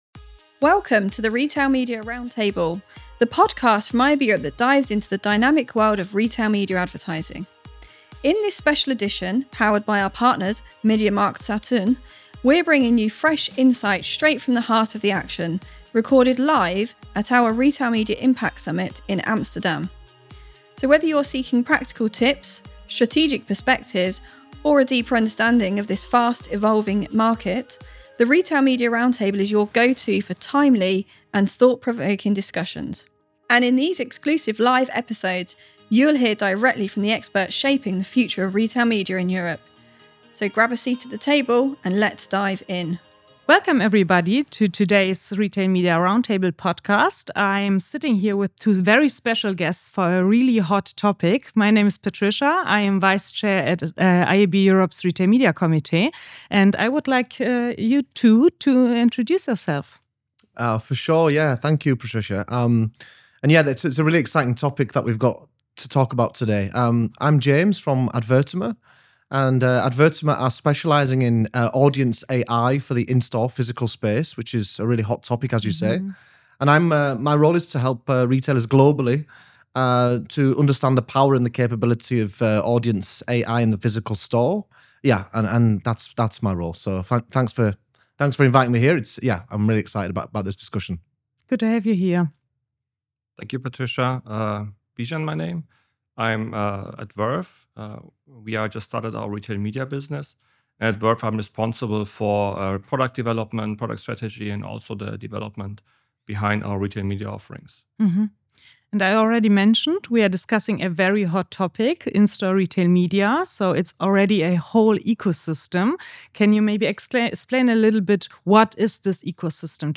A 15min IAB Europe live podcast on building in-store retail media networks: ecosystem, incrementality, rollout & Audience AI
Retail media is evolving from isolated pilots to scalable networks. This 15-minute podcast, recorded live at the IAB Europe Retail Media Summit in Amsterdam, brings together IAB Europe, Verve Retail Media, and Advertima, to discuss what it takes to build in-store retail media networks from ecosystem design to measurement and roll-out.